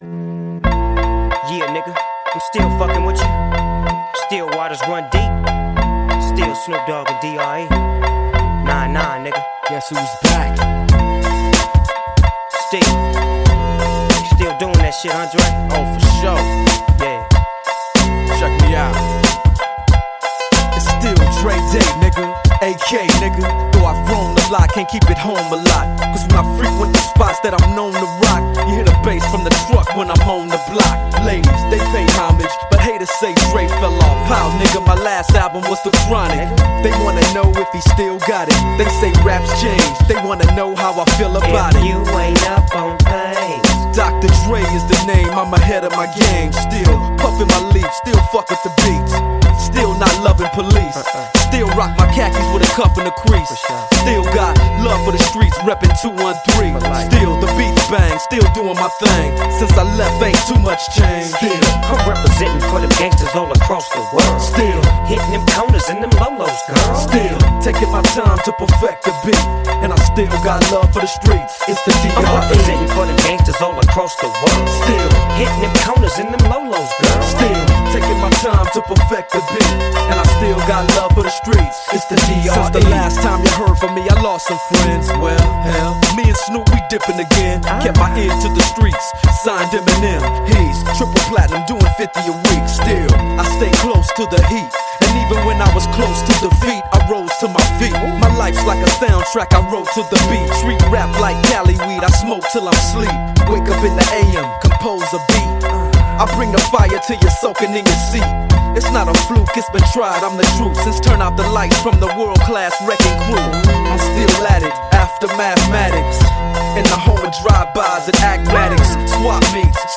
Жанр: Rap/Hip Hop